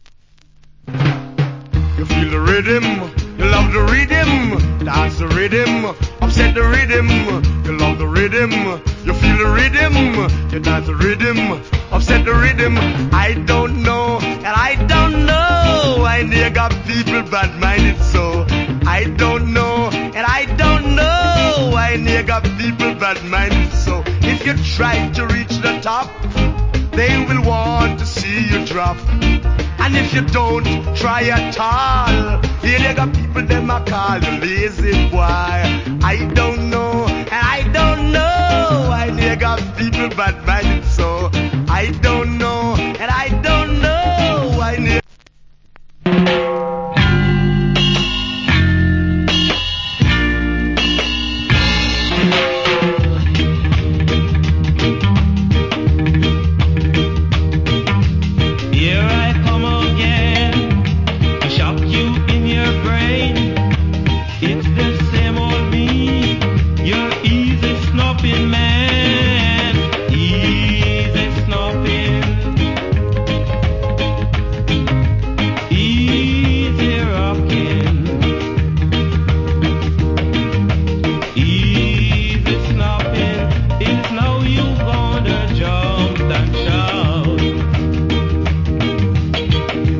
Wicked Rock Steady.